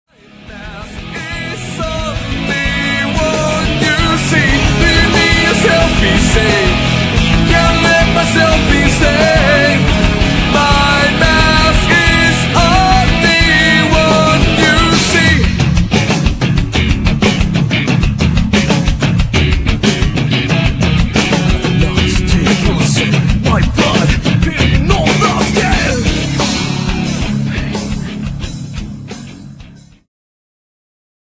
重く、畝ル、変拍子、まさに新世紀型プログレッシブ/ラウドシーンの新旗手に相応しいサウンド。
仄暗く渦巻く美しいメロディーとスクリーモは圧巻！！！